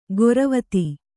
♪ goravati